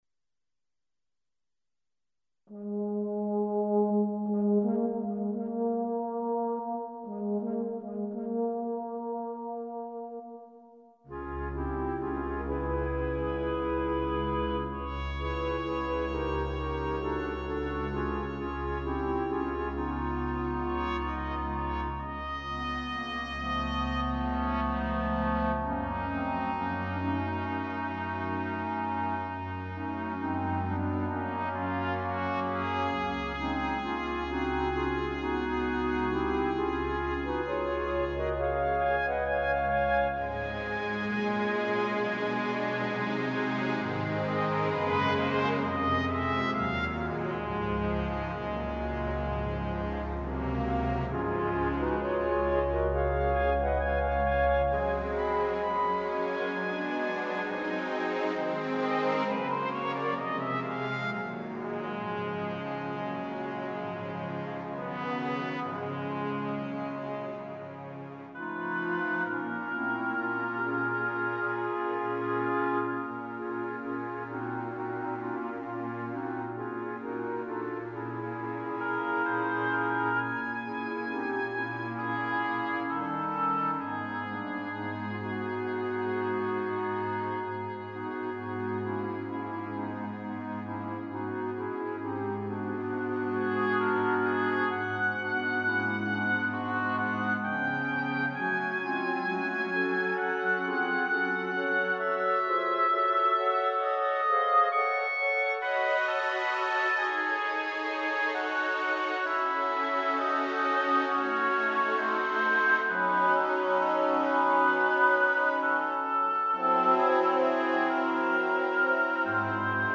CLASSICAL MUSIC ; JAZZ